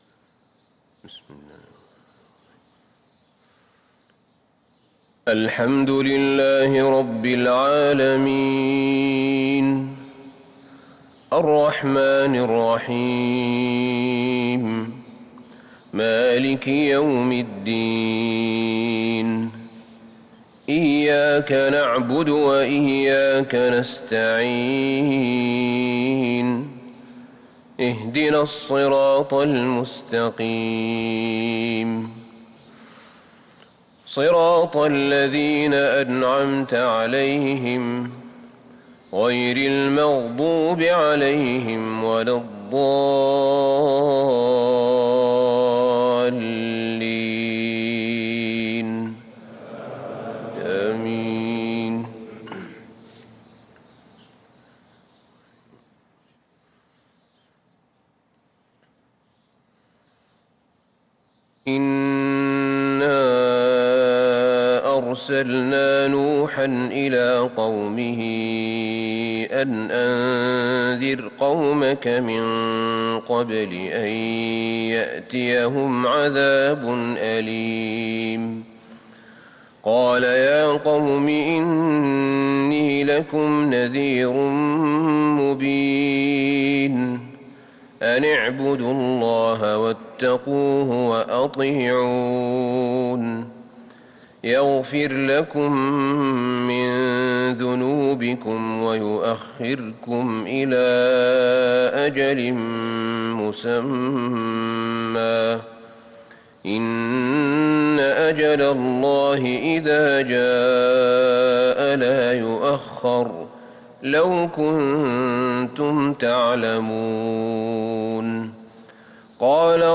صلاة الفجر للقارئ أحمد بن طالب حميد 12 ربيع الآخر 1443 هـ